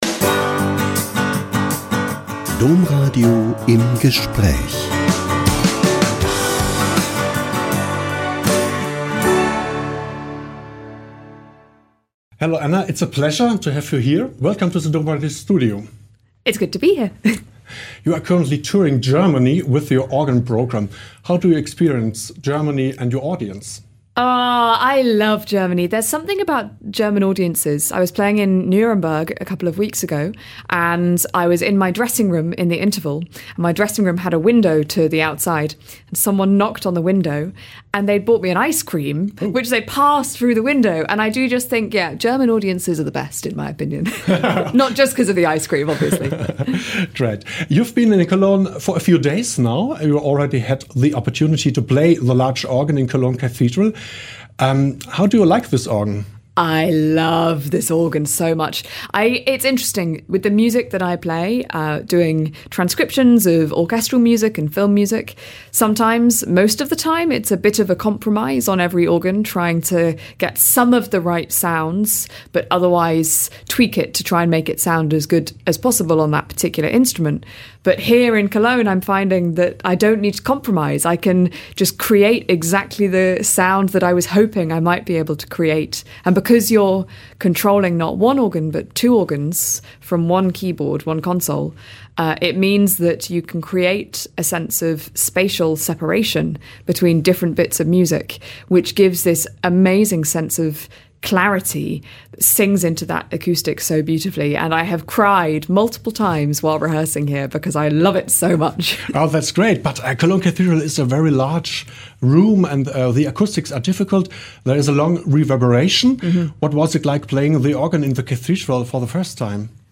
Organist Anna Lapwood talks about her concert in Cologne Cathedral